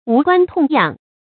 無關痛癢 注音： ㄨˊ ㄍㄨㄢ ㄊㄨㄙˋ ㄧㄤˇ 讀音讀法： 意思解釋： 痛癢：比喻切身相關的事。指與自身利害沒有關系。